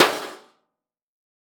Impact Sheet Metal Impact Hammer Hit Car Part 01.wav